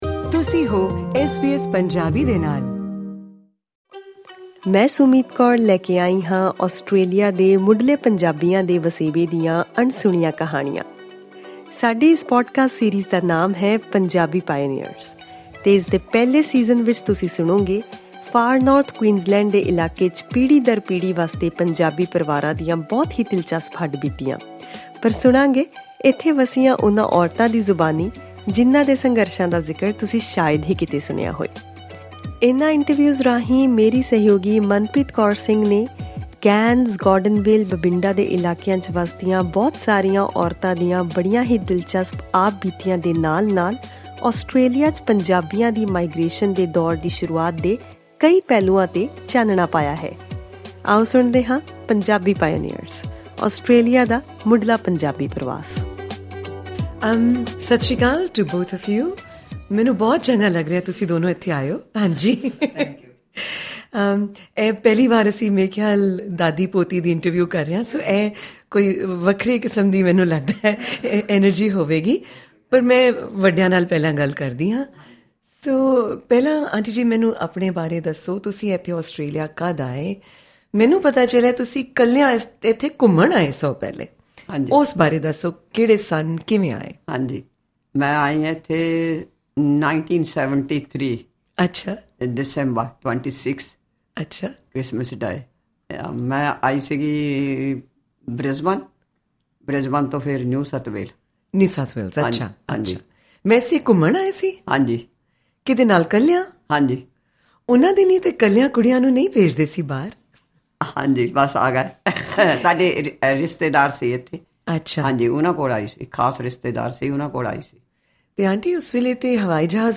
'I built a life here’: A grandmother and granddaughter on migration, identity and language across generations
Click on the audio icon to listen to the full interview in Punjabi.